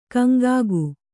♪ kaŋgāgu